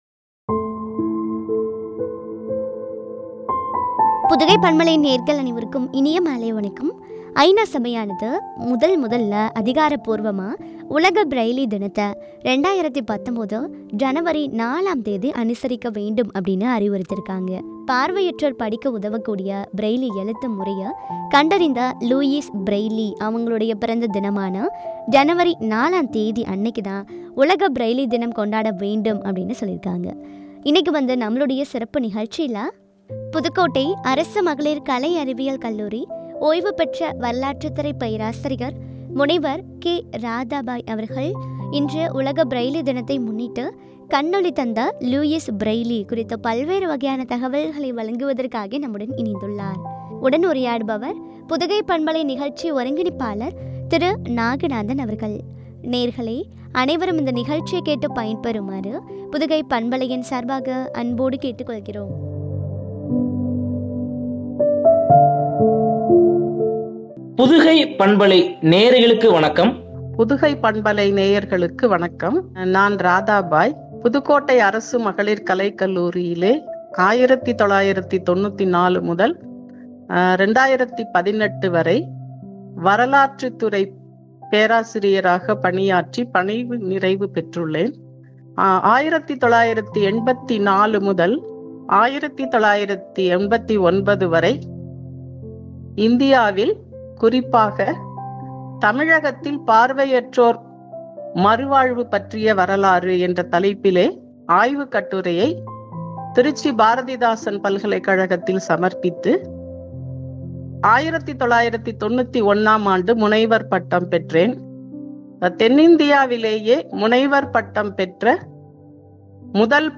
” கண்ணொளி தந்த லூயிஸ் பிரெய்லி” குறித்து வழங்கிய உரையாடல்.